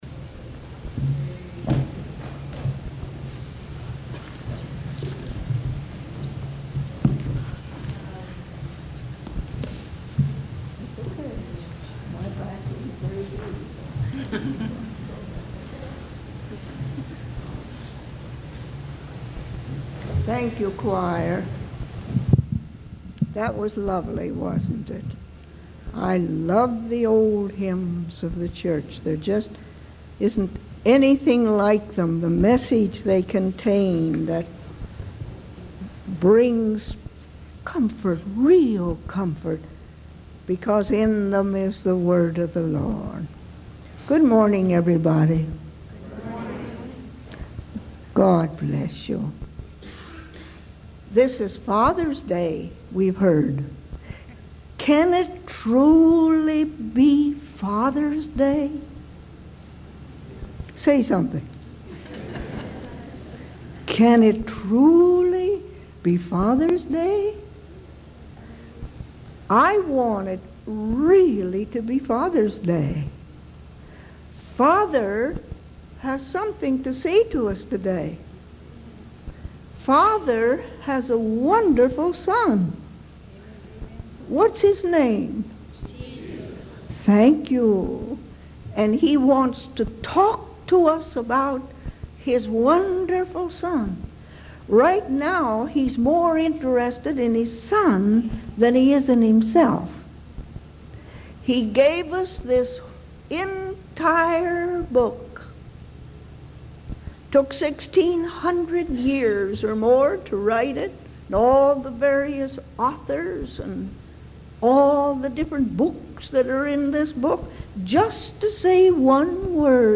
In this sermon, the preacher emphasizes the importance of not missing out on the future with God.